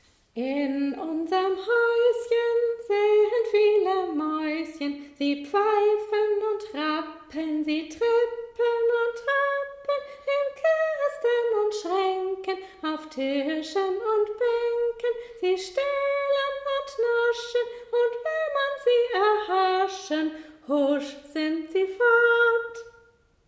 Diese Woche wurde ein pentatonisches Lied für Sie eingesungen. Sie können damit einen ersten Eindruck bekommen, die Aufnahme ist aber auch zum Mitsingen und Üben des Liedes geeignet.